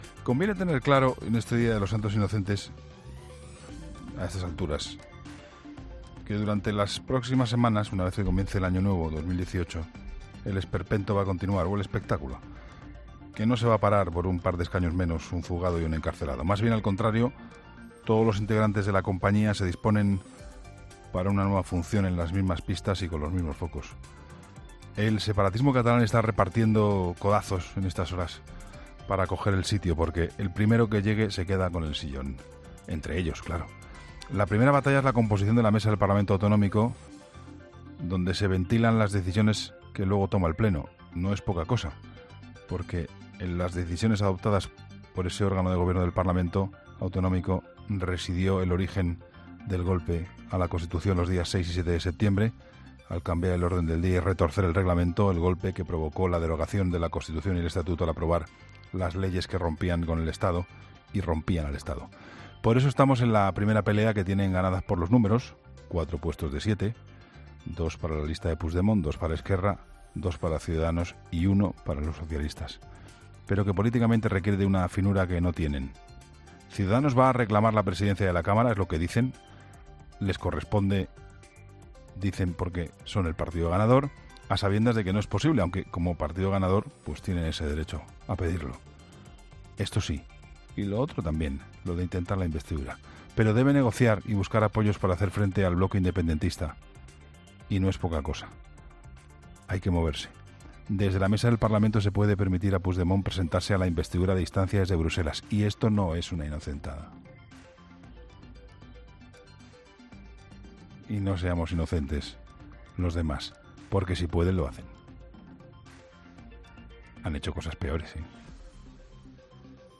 La crónica de Juan Pablo Colmenarejo